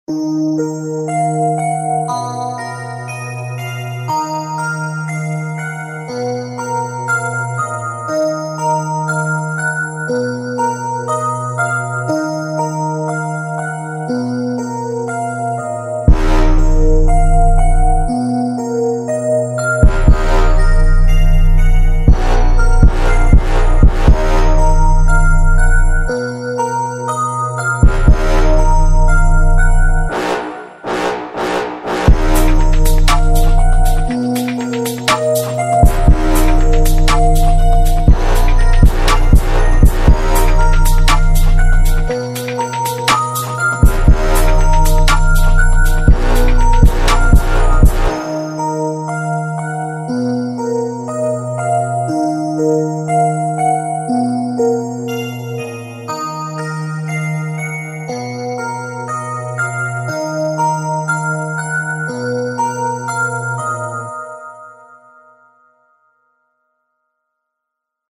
预期重的808音调，脆脆的军鼓和掌声，猛烈的踢鼓，快速的踩hat和旋律会从一开始就一直持续出现在听众的耳朵中。